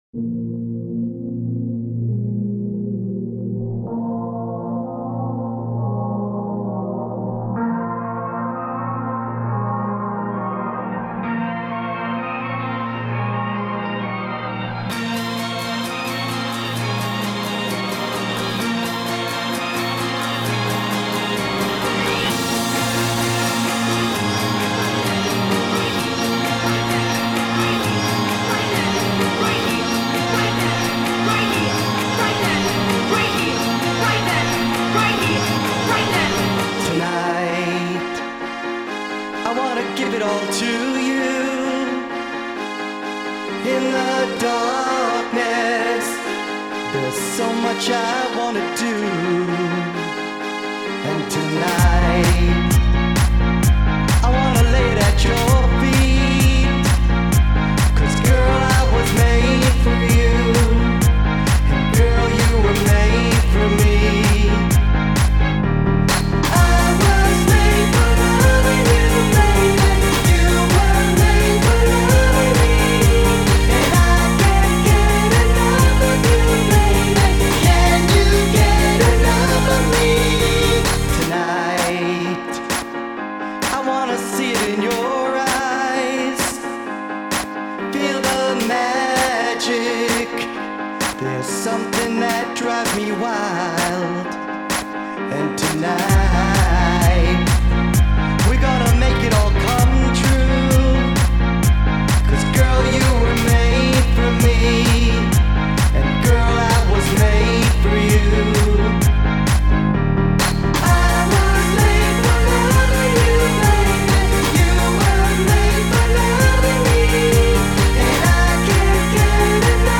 Acapella
Instrumentale